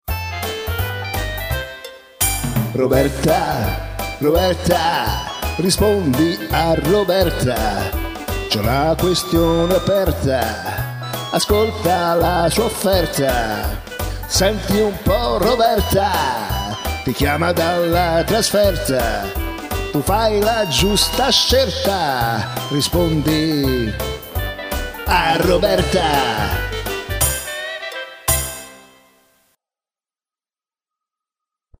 Una suoneria personalizzata